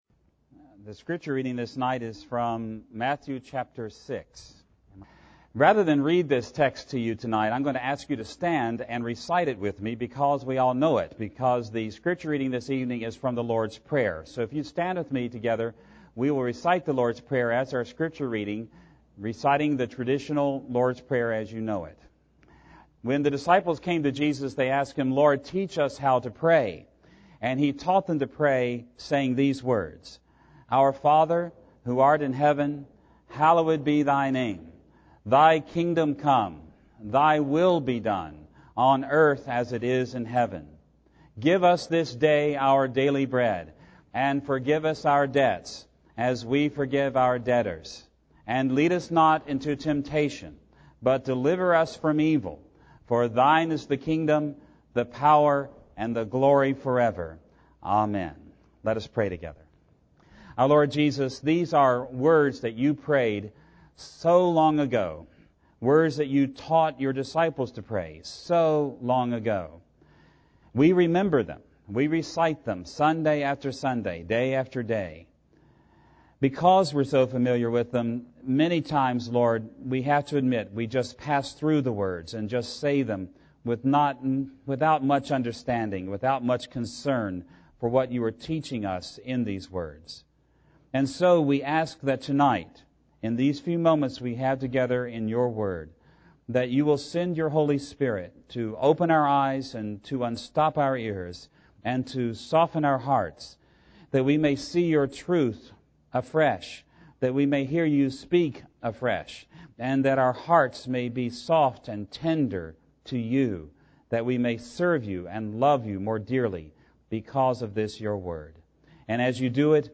No attempt has been made, however, to alter the basic extemporaneous delivery style, or to produce a grammatically accurate, publication-ready manuscript conforming to an established style template.